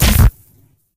tele_damage_2.ogg